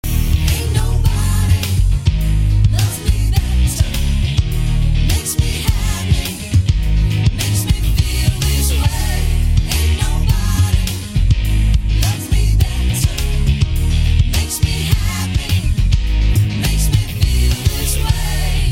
cover bands